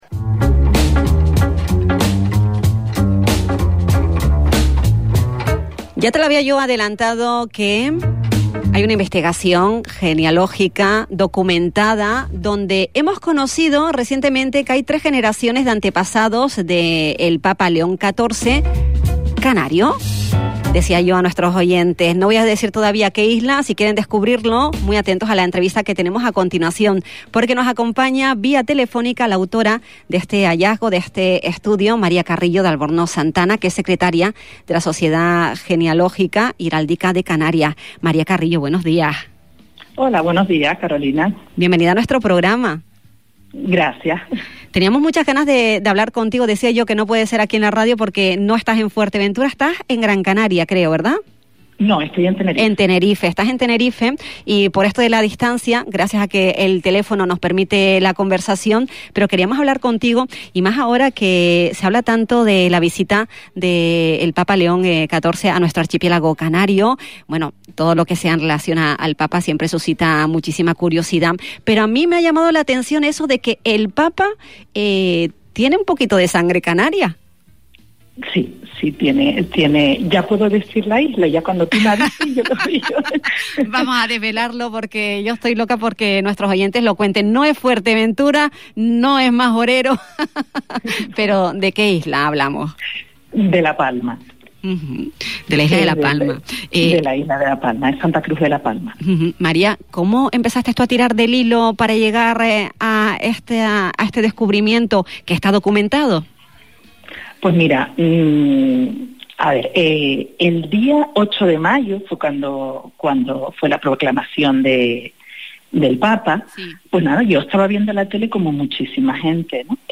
Un descubrimiento que conecta al Papa con La Palma Durante una entrevista en el programa «La Mañana Xtra»